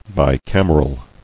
[bi·cam·er·al]